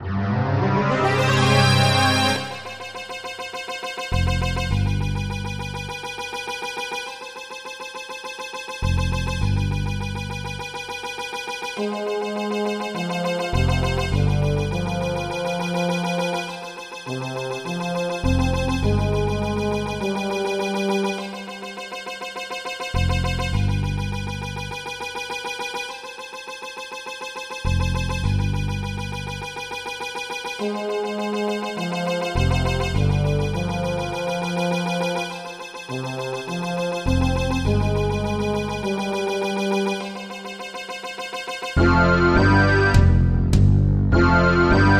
midi/karaoke